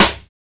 Snare (Gas Drawls).wav